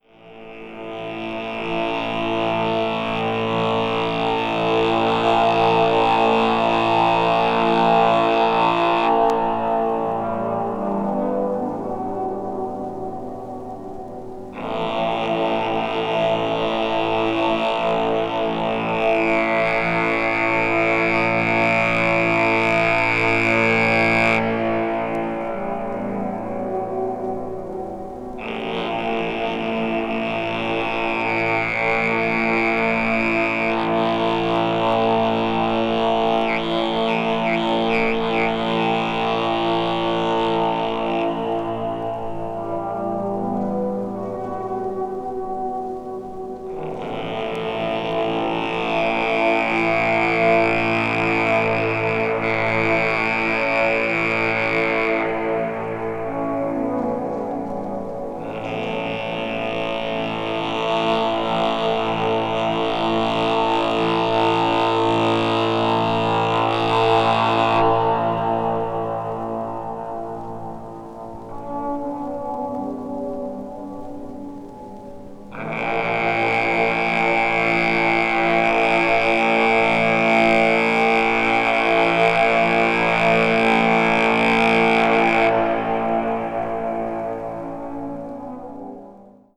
avant-garde   experimental   free improvisation   meditation